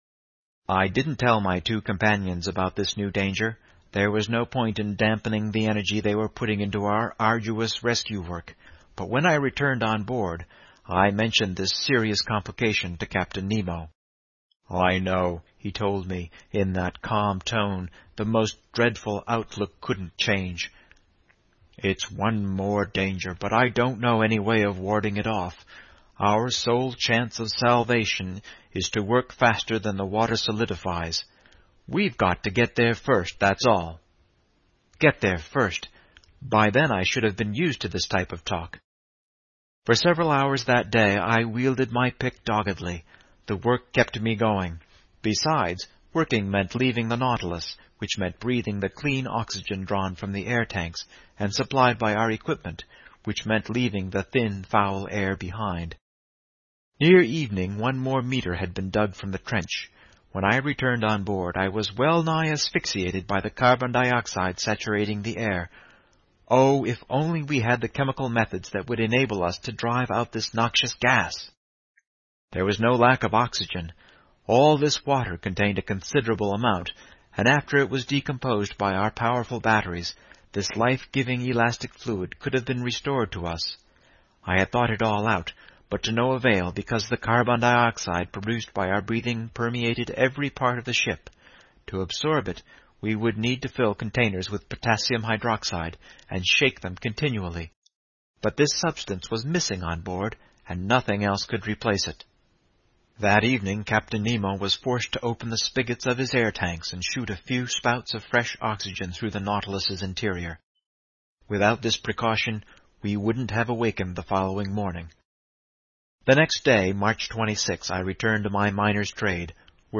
英语听书《海底两万里》第472期 第29章 缺少空气(4) 听力文件下载—在线英语听力室
在线英语听力室英语听书《海底两万里》第472期 第29章 缺少空气(4)的听力文件下载,《海底两万里》中英双语有声读物附MP3下载